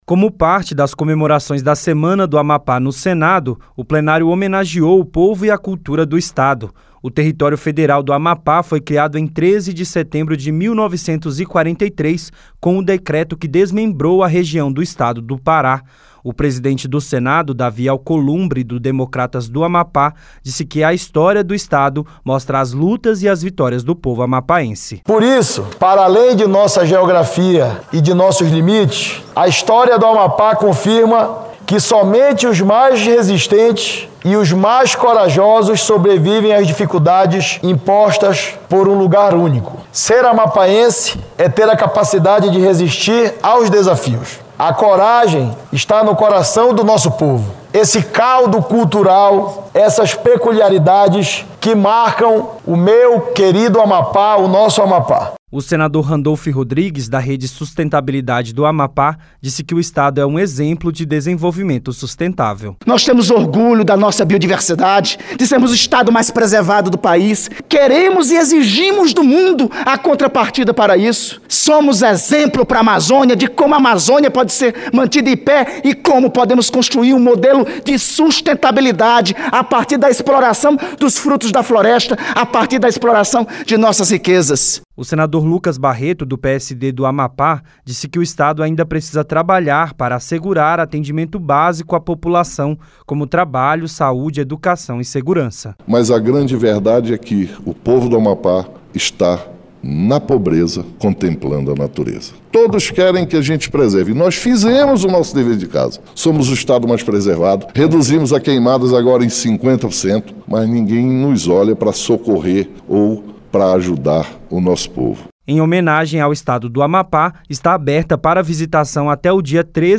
Sessão especial
O Senado fez uma sessão especial em homenagem aos 76 anos de criação do território federal do Amapá. Senadores lembraram a atuação do estado na preservação da Amazônia e alertaram para a necessidade de melhorias nos serviços para a população.